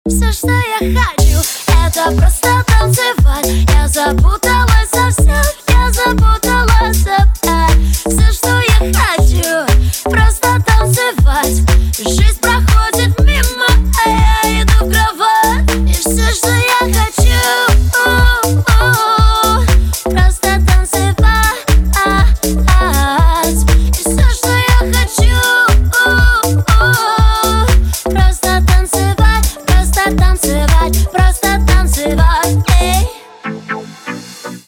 • Качество: 320 kbps, Stereo
Поп Музыка